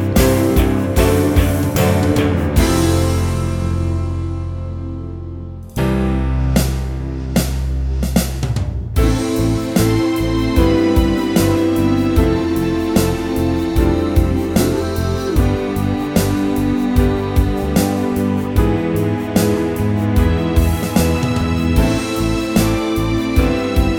Pop (2000s)